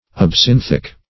Search Result for " absinthic" : The Collaborative International Dictionary of English v.0.48: Absinthic \Ab*sin"thic\, a. (Chem.)